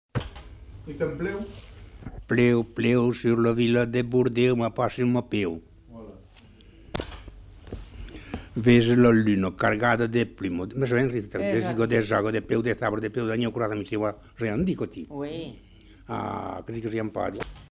Lieu : Saint-Rémy-de-Gurson
Genre : forme brève
Effectif : 1
Type de voix : voix d'homme
Production du son : récité
Classification : formulette enfantine